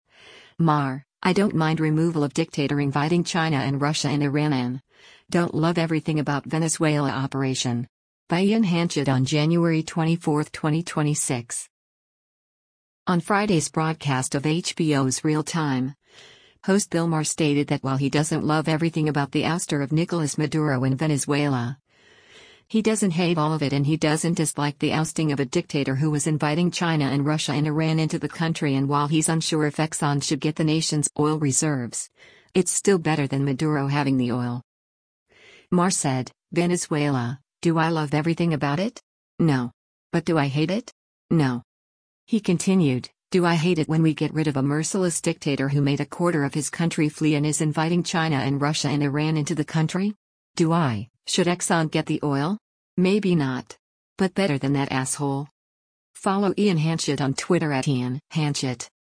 On Friday’s broadcast of HBO’s “Real Time,” host Bill Maher stated that while he doesn’t love everything about the ouster of Nicolas Maduro in Venezuela, he doesn’t hate all of it and he doesn’t dislike the ousting of a dictator who was “inviting China and Russia and Iran into the country” and while he’s unsure if Exxon should get the nation’s oil reserves, it’s still better than Maduro having the oil.